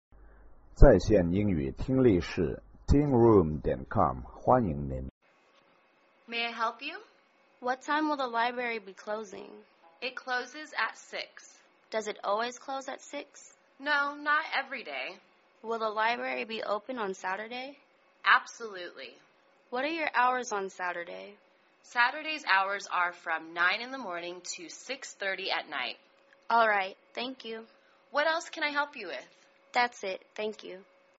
英语情景对话-Asking Questions(2) 听力文件下载—在线英语听力室